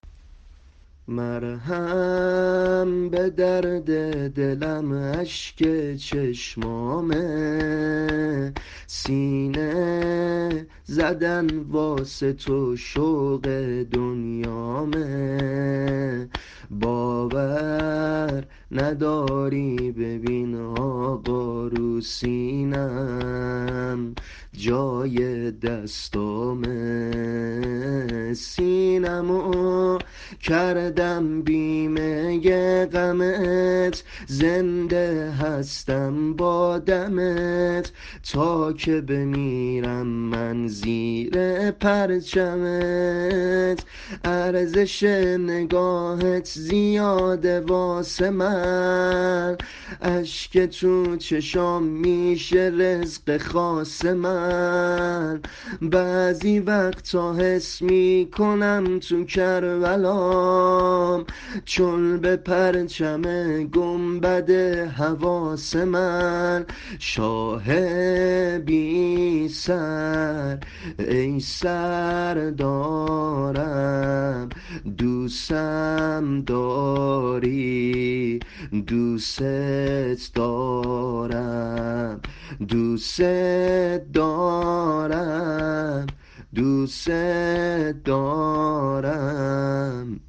سبک شور سینه زنی مناجات با امام حسین